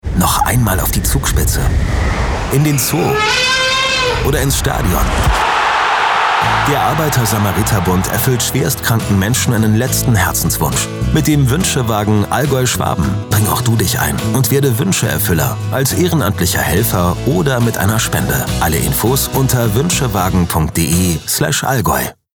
zum Wünschewagen-Radiospot